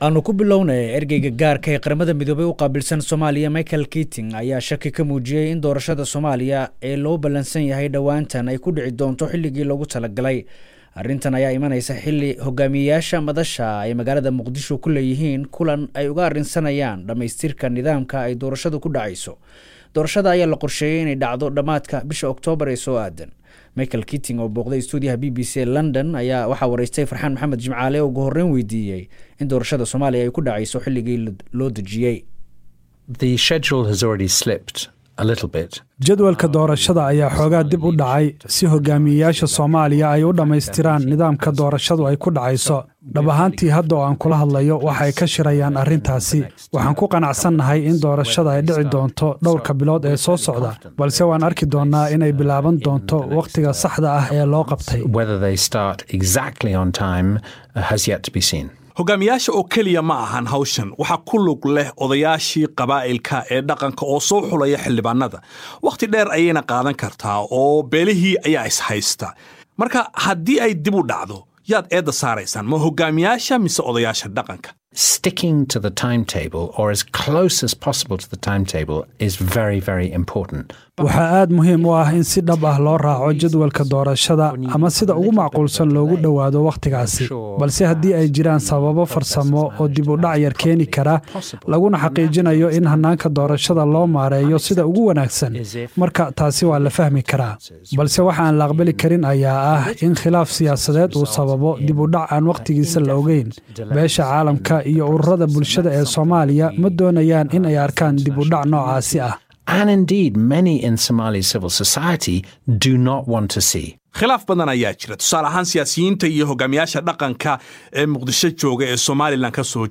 10 sept 2016 (Puntlandes) Ergeyga Qaramada Midoobey u qaabilsan Soomaaliya Micheal Keating oo wareysi siiyay Laanta afka Soomaaliga ee BBC-da ayaa sheegay inay qaabka ay Doorasha Soomaaliya ay u dhacayso uu ku yimid wada xaajood Siyaasadeed oo mudo dheer socday.